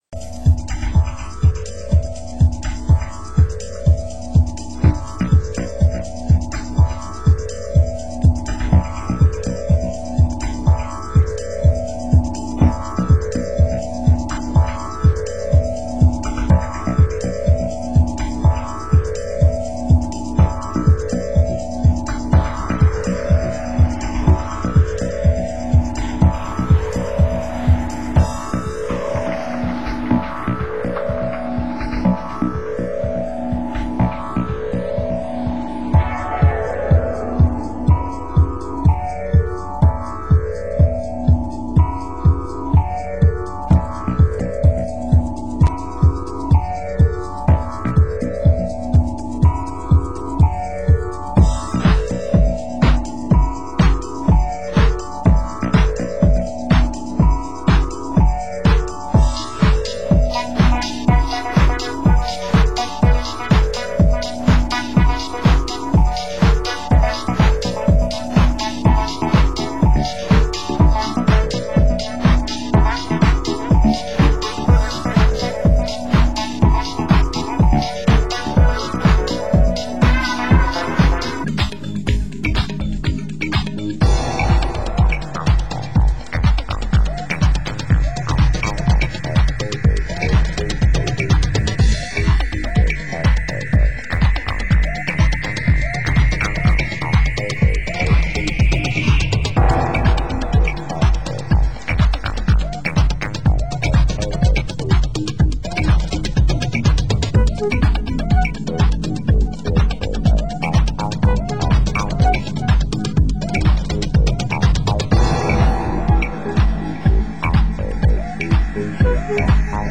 Genre: Deep House
Genre: UK House